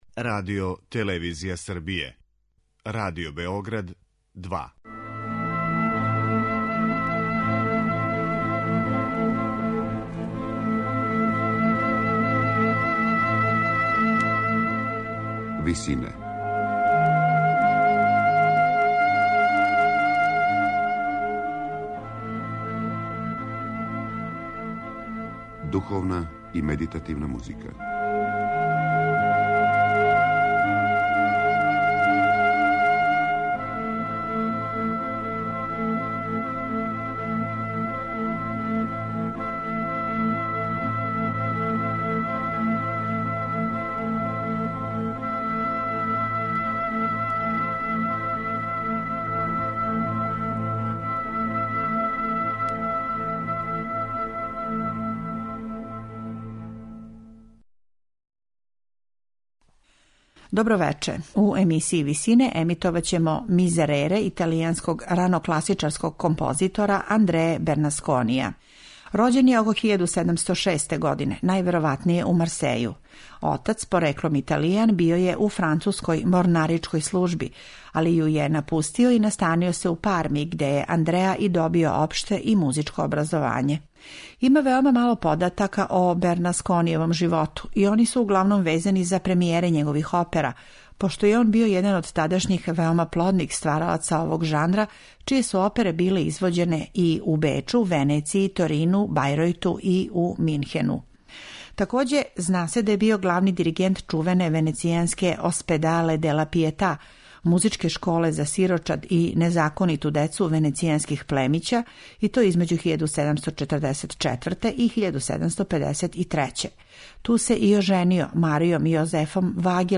Снимак је остварен на концерту у Лугану, јануара 2011. године, у Сали "Моло аудиторијума". Дело су извели чланови ансамбла "И Барокисти", као и солисти и хор Швајцарске РТВ, а дириговао је Дјего Фазолис.